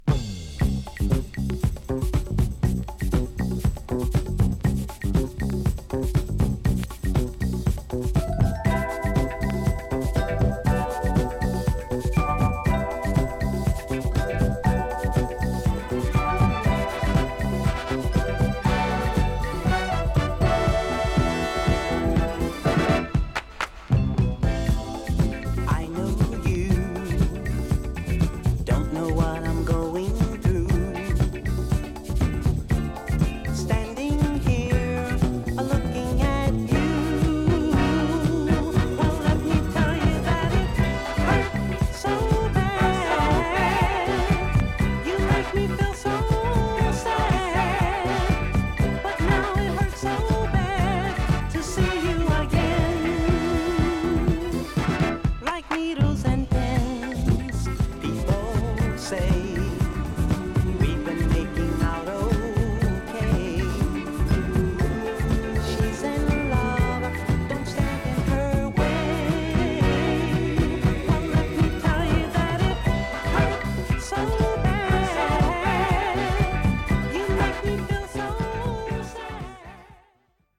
オリジナルよりハイテンポで、華麗なボーカル＆コーラスが絡み合う最高のテイクの一つでしょう。